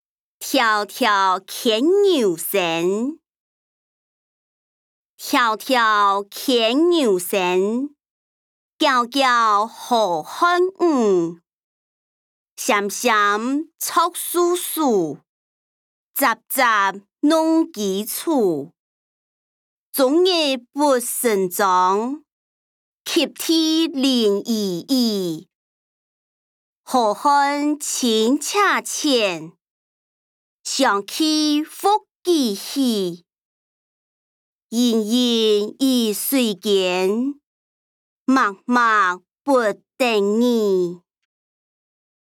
古典詩-迢迢牽牛星音檔(四縣腔)